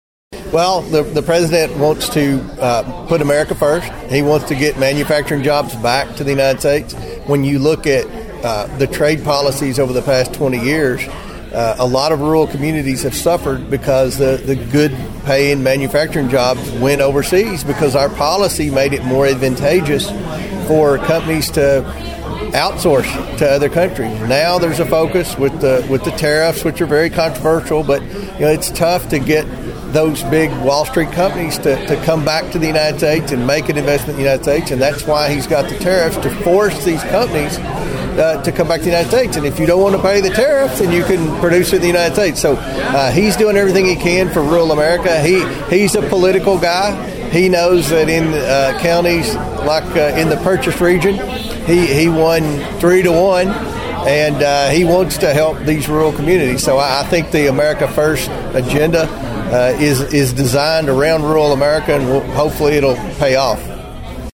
Following the ceremony, Congressman Comer was asked by Thunderbolt News about the current agenda of President Trump to provide needed assistance for rural communities.(AUDIO)